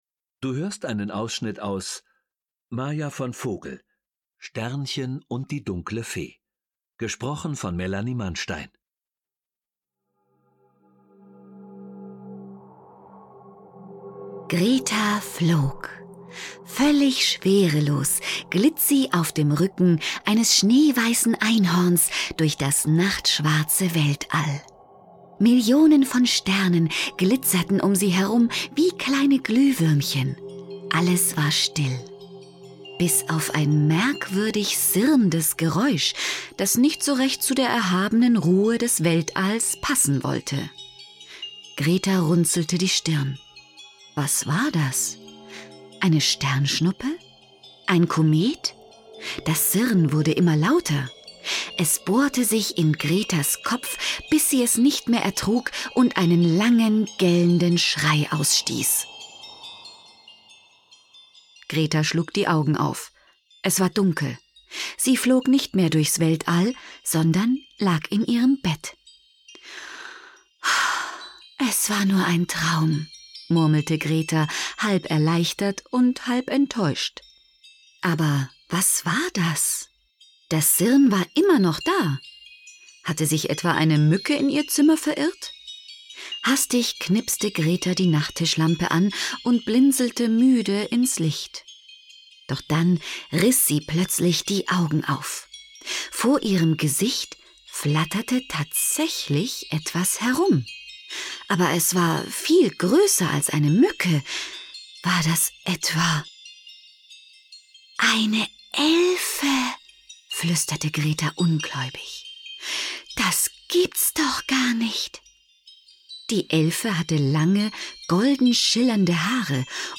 Schlagworte Abenteuer • Einhorn • Einhorn / Einhörner; Kinder-/Jugendliteratur • Einhorn; Kinder-/Jugendliteratur • Fee • Hörbuch; Lesung für Kinder/Jugendliche • Sternchen • Zaubereinhorn